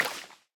sounds / step / wet_grass6.ogg
wet_grass6.ogg